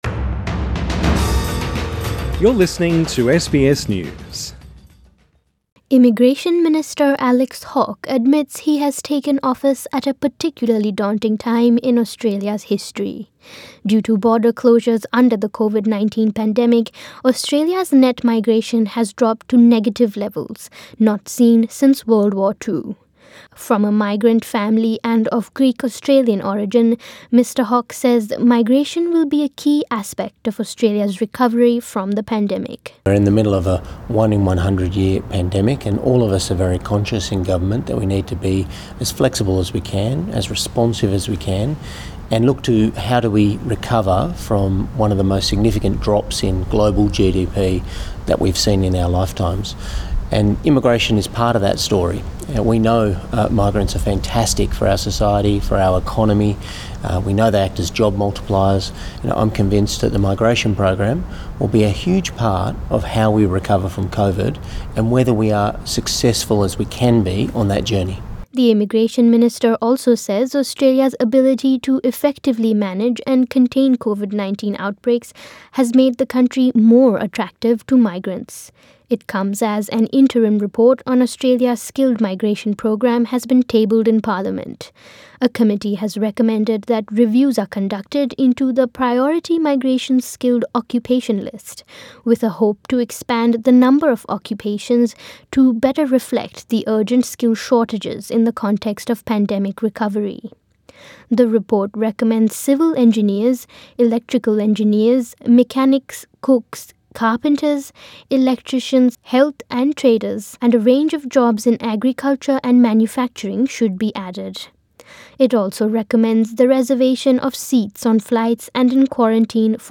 In an interview with SBS News, Mr Hawke discussed the impacts of COVID-19 on migration and a post-pandemic Australia.